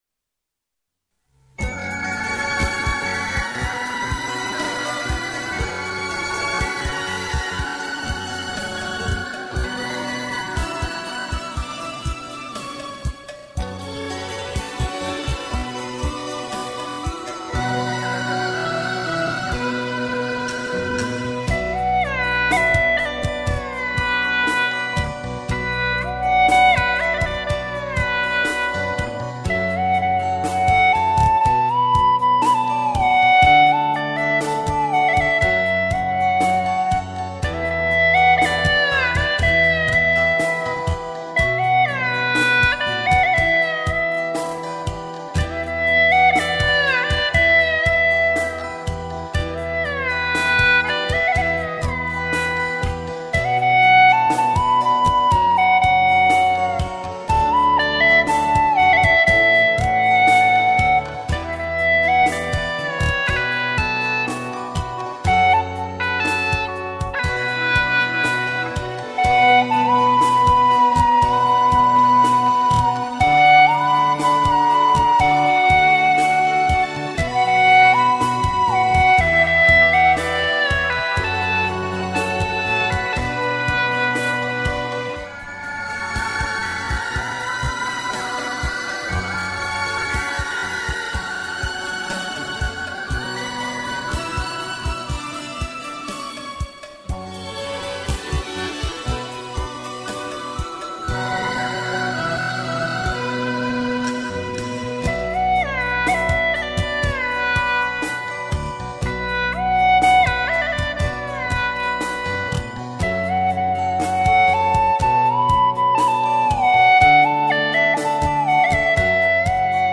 调式 : D 曲类 : 少儿